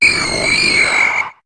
Cri de Fantominus dans Pokémon HOME.